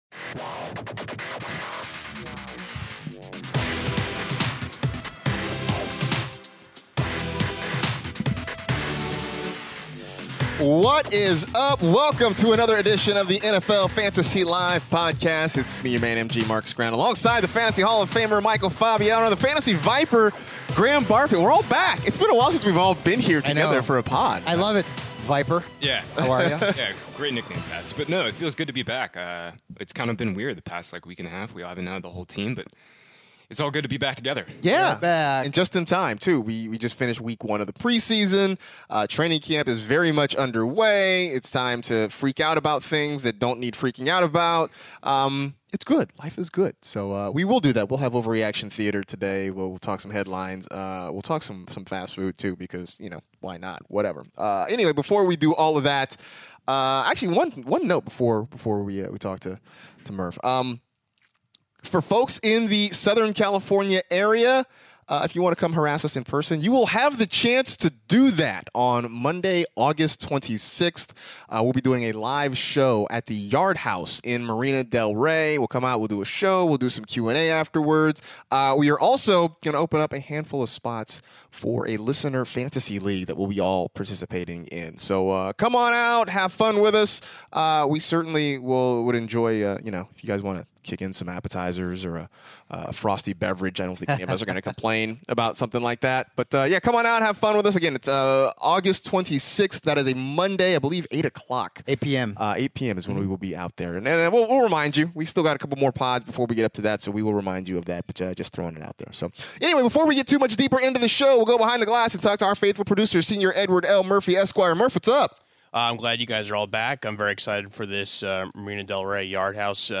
are all back in studio together to record a brand new Fantasy Live podcast!